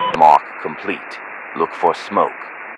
Radio-jtacSmokeOK4.ogg